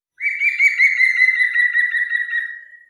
Grünspecht Ruf
Gruenspecht-Ruf-Voegel-in-Europa.wav